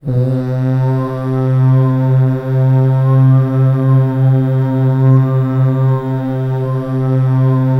DRONER    -L.wav